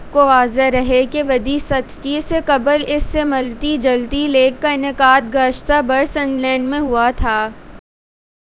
deepfake_detection_dataset_urdu
deepfake_detection_dataset_urdu / Spoofed_TTS /Speaker_10 /12.wav